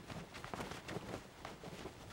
cloth_sail14.R.wav